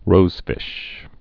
(rōzfĭsh)